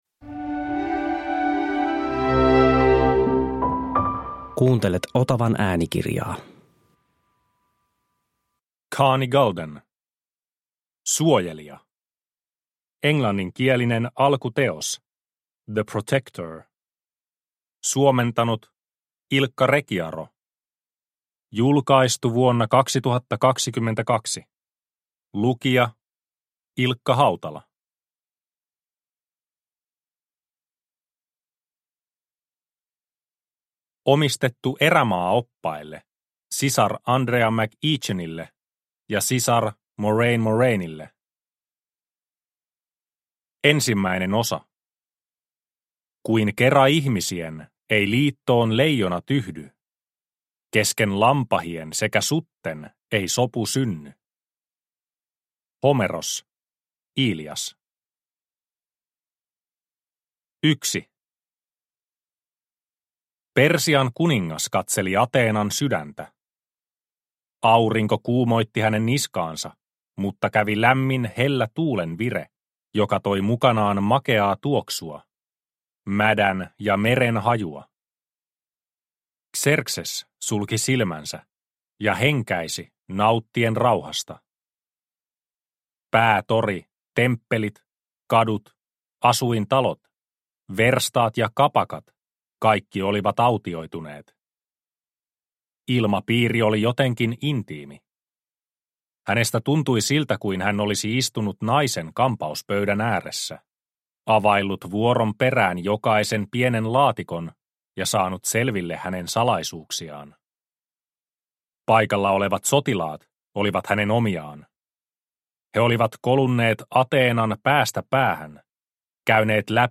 Suojelija – Ljudbok – Laddas ner